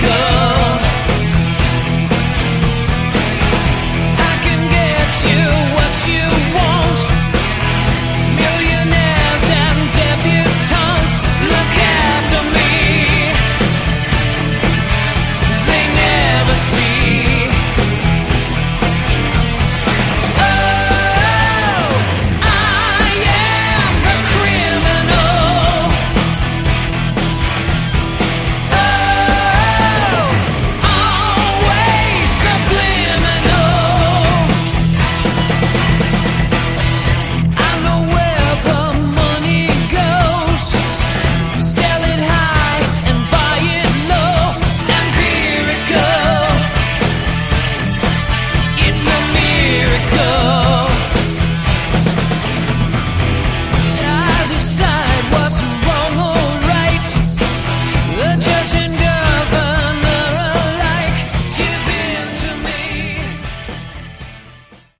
Category: Melodic Metal
vocals
keyboards
drums